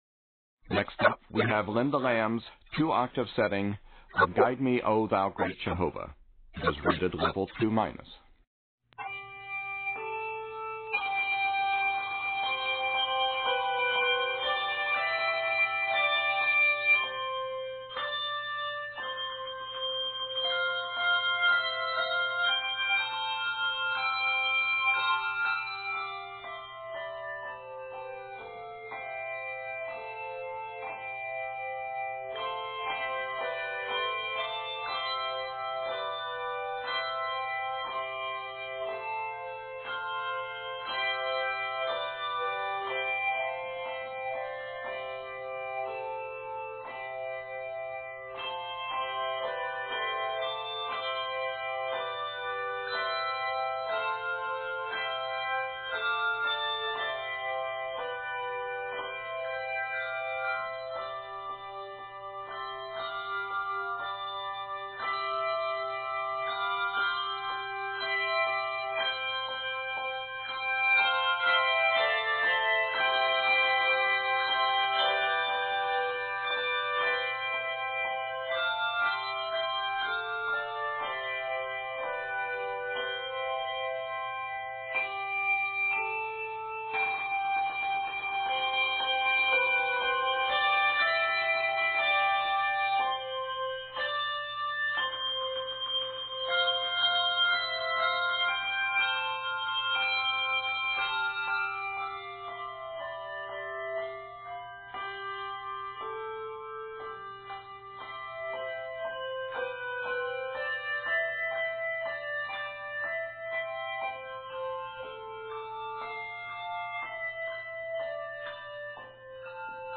It's set for a full, 2 octave, handbell choir.